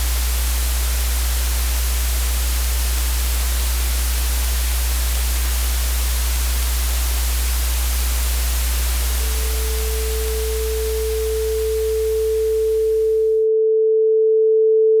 它以“真实”开始——房间里 60Hz 的嗡嗡声，灰尘的嘶嘶声，某物因自身张力而断裂的随机咔哒声。
然后，它慢慢地变得“可读”。
最后，它变成了一个纯粹的 440Hz 正弦波。
完美。干净。优化。
这听起来像是一个停止应答的世界的拨号音。